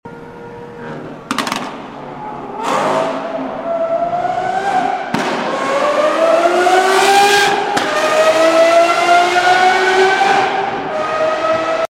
AVENTADOR SVJ COM RONCO DE Sound Effects Free Download